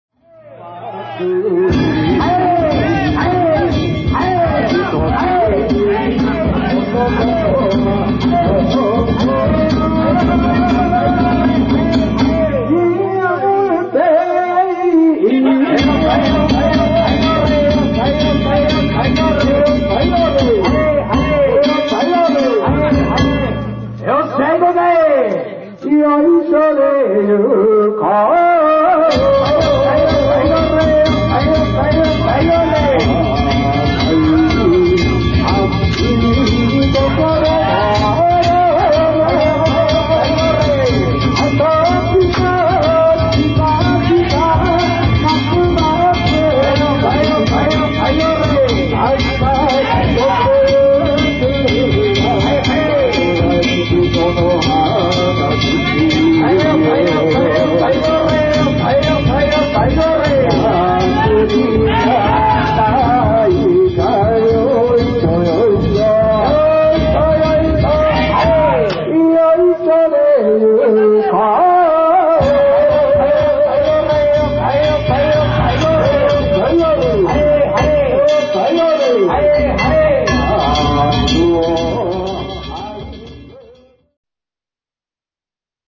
西町地車お披露目曳行
遠くから曳き唄の声が聞こえてきます。
曳き唄の声を響かせながら太子町山田の村の中を進みます。